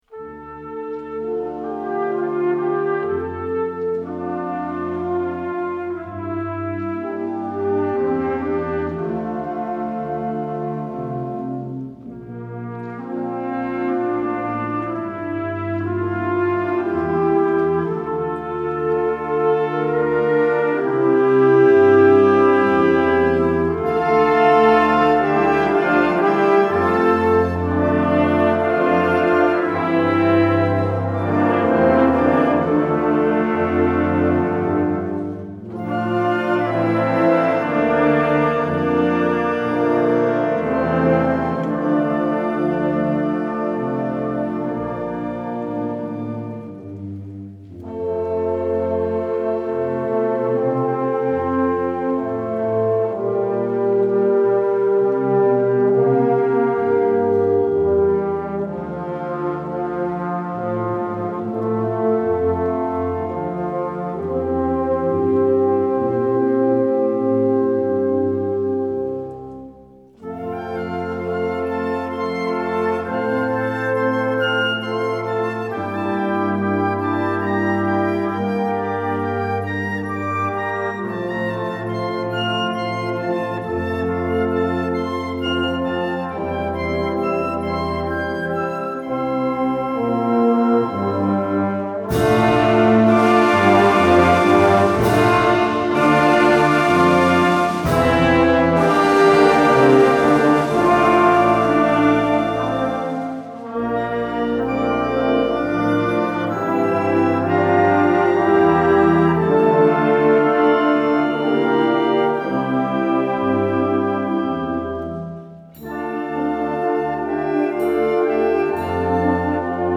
Klagelied für Blasorchester…
Feierliche/Sakrale Blasmusik